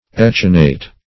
Search Result for " echinate" : The Collaborative International Dictionary of English v.0.48: Echinate \Ech"i*nate\, Echinated \Ech"i*na`ted\, a. [L. echinatus.